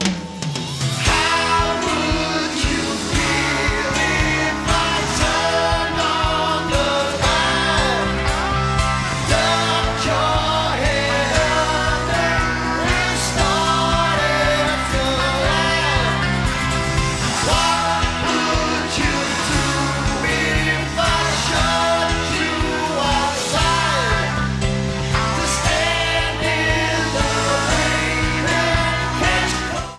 CD Comments: No noticeable sound differences. .